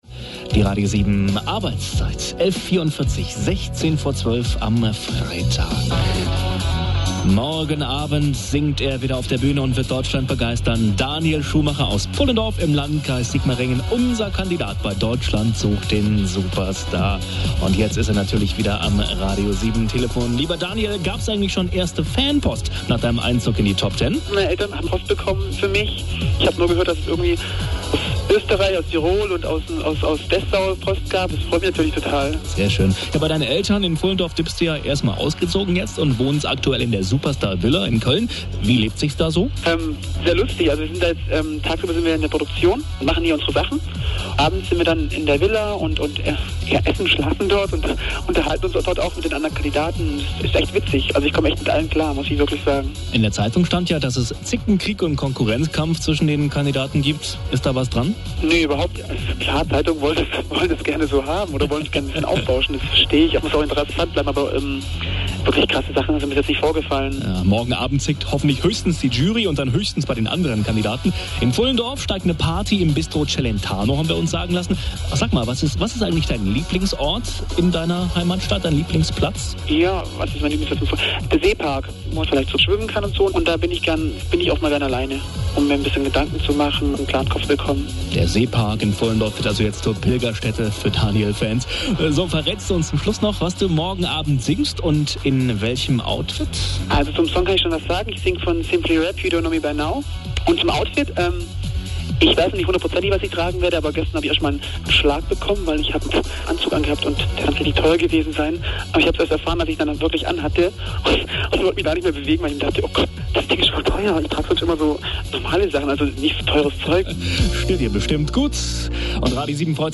Interview mit mir...